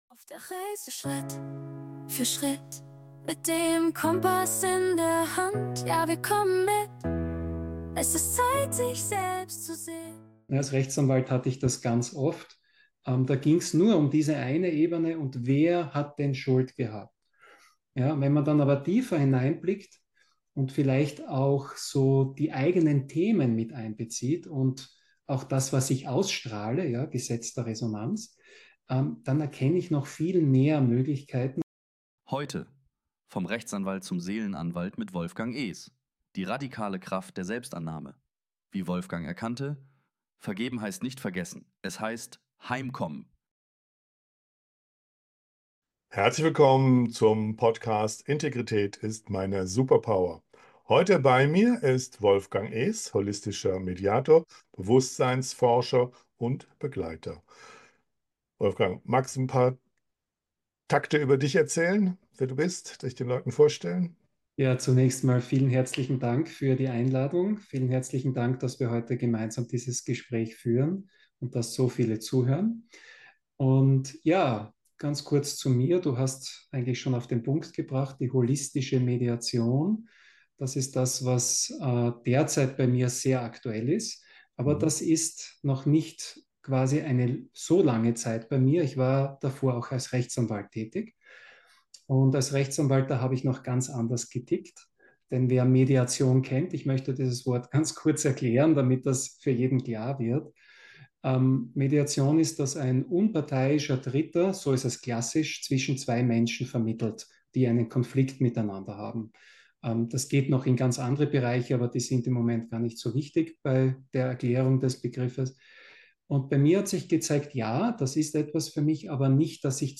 In diesem zutiefst ehrlichen Gespräch begegnen sich zwei Suchende mit offenen Herzen und geschliffenen Fragen: